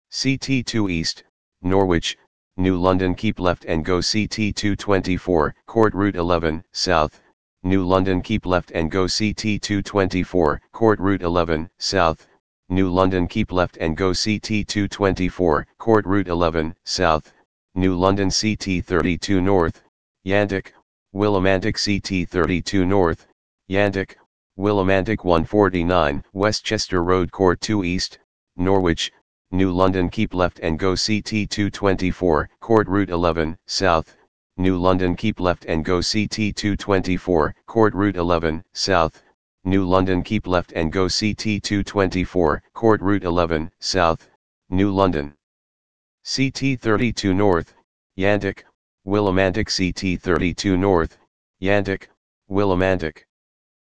gps instructions recreated
GPS recreation.wav